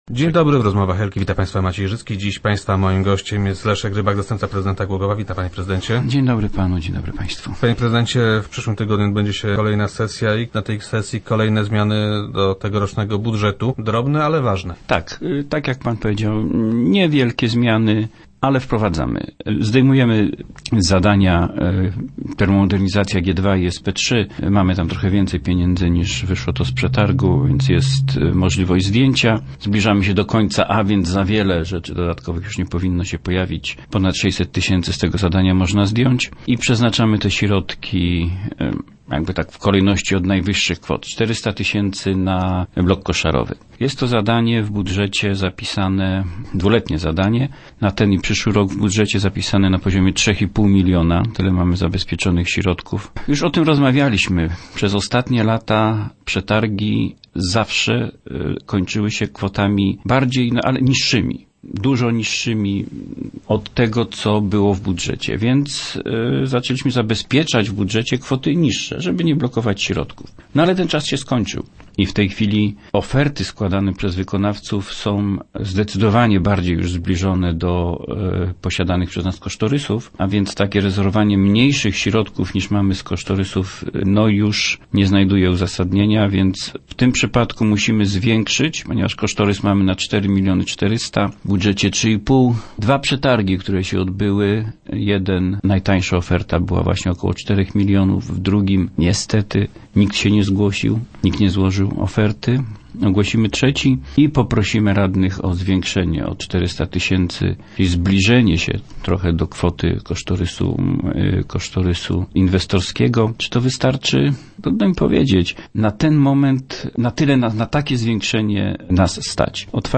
Do kolejnego nie przystąpiła żadna firma – informuje Leszek Rybak, zastępca prezydenta Głogowa.